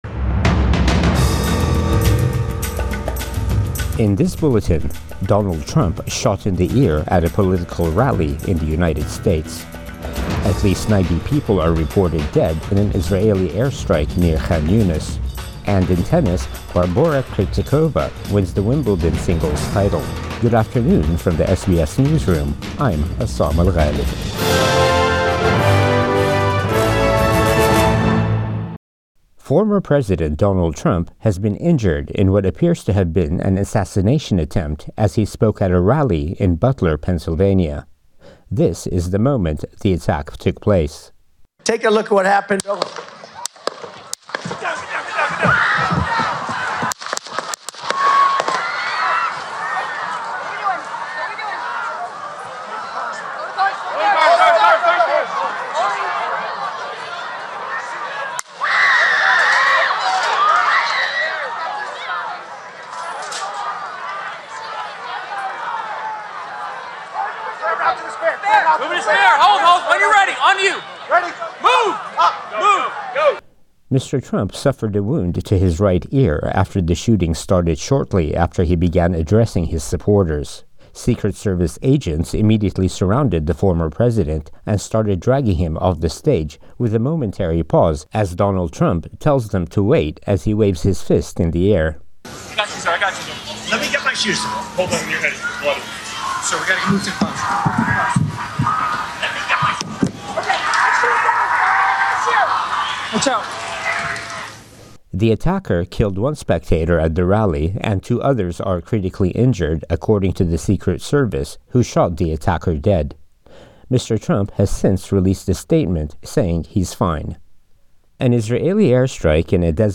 Then another four or five shots.